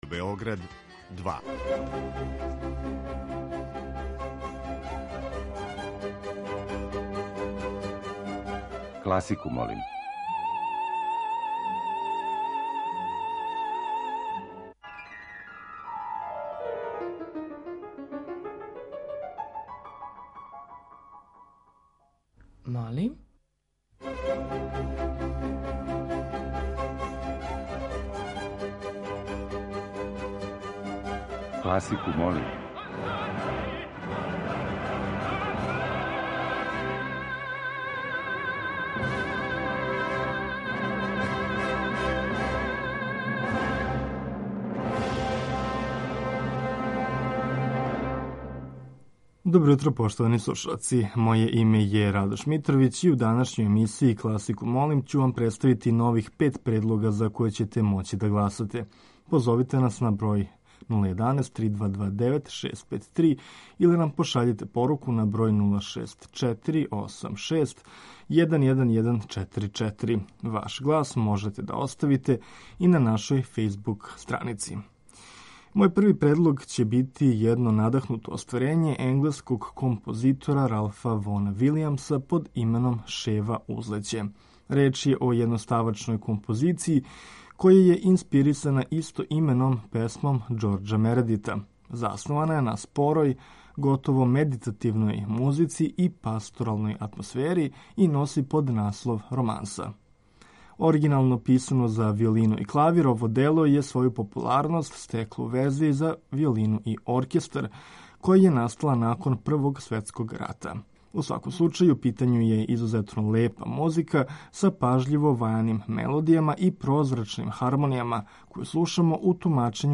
Окосница овонедељне емисије Класику, молим биће оперске увертире.